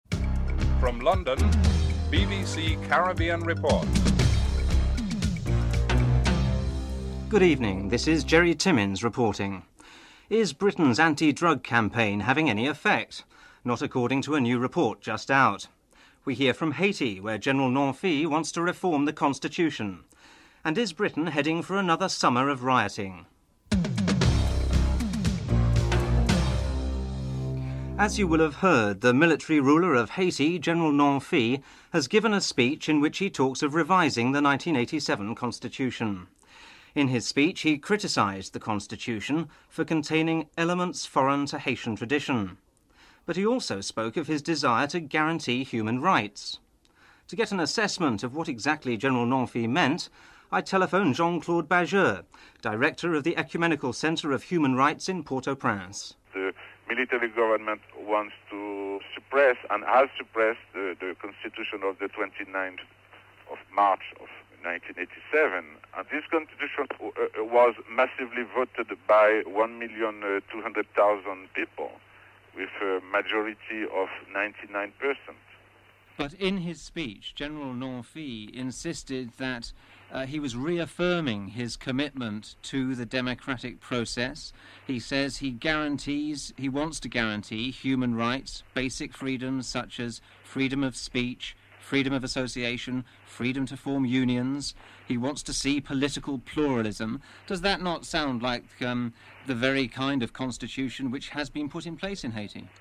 5. Sport segment.